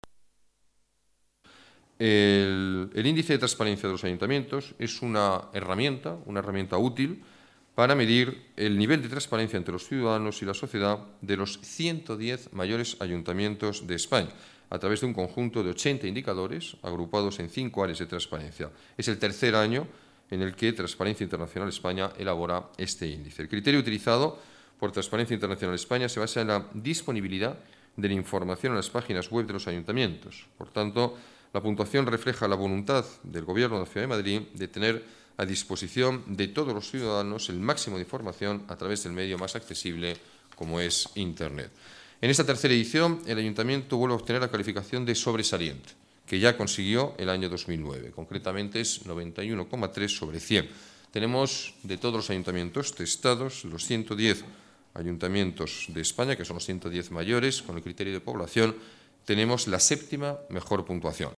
Nueva ventana:Declaraciones del alcalde: transparencia administrativa